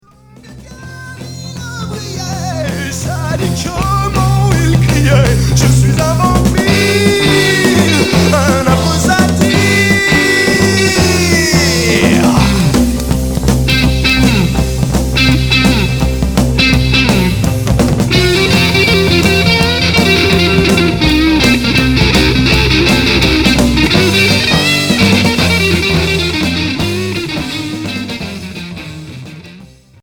Rock psyché